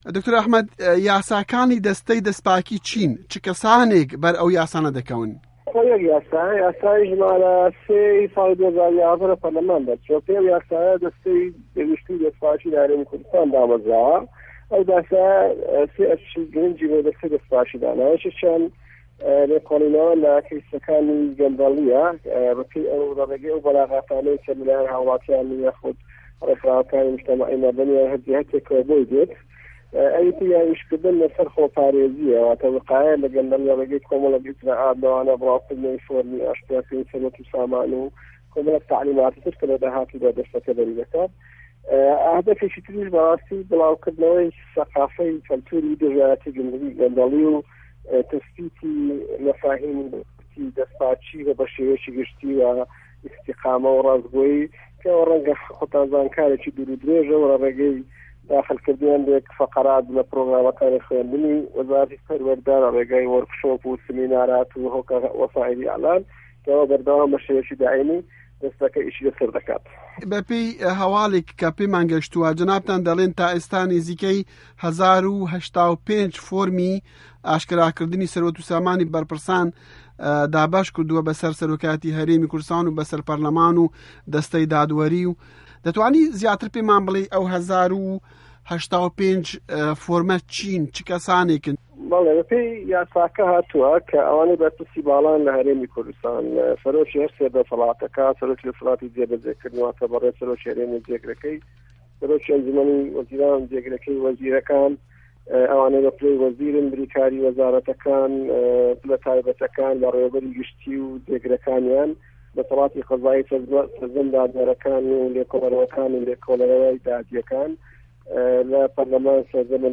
وتووێژی ئه‌حمه‌د ئه‌نوه‌ر